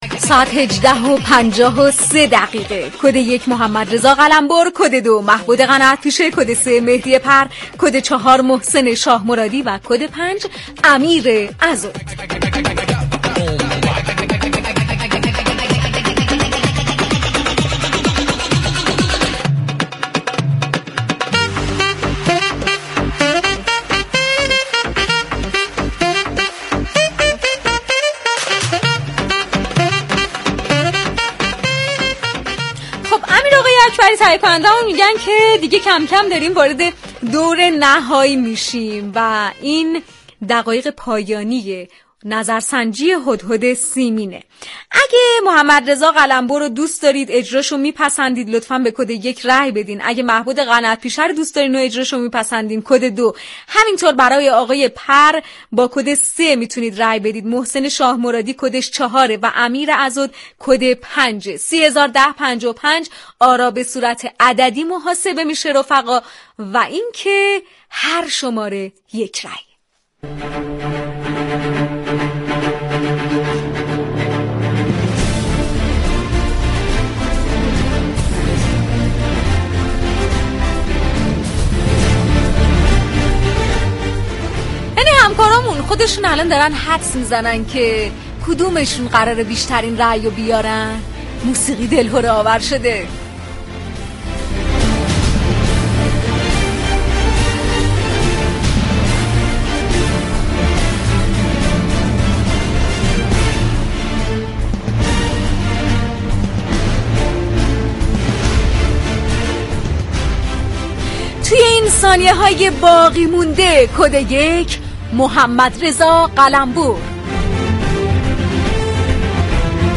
این مجله رادیویی با صفحات متنوع و طنز تلاش می كند به كمك تعامل با مخاطبان فضای شاد و صمیمی ایجاد كند.